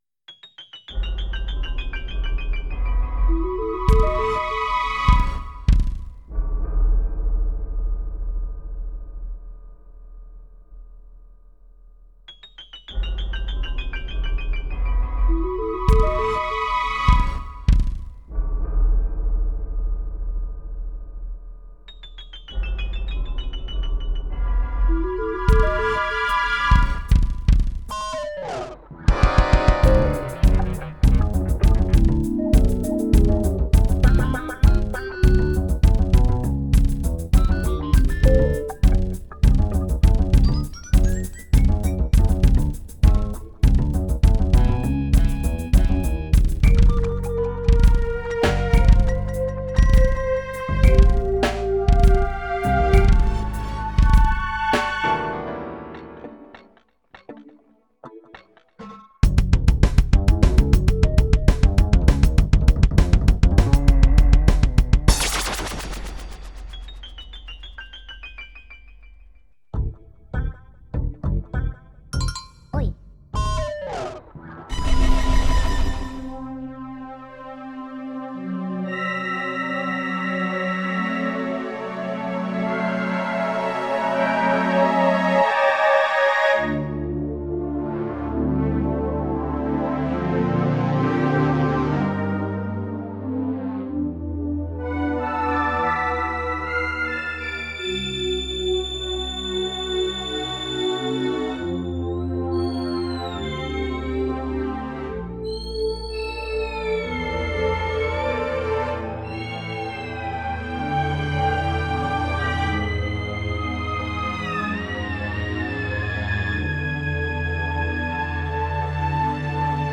short inhale vocal performance